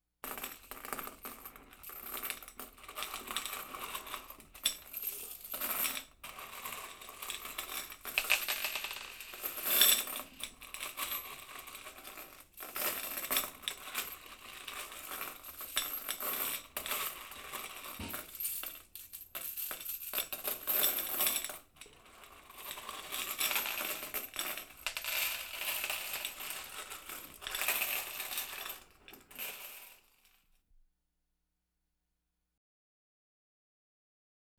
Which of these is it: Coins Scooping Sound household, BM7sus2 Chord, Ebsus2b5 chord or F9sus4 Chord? Coins Scooping Sound household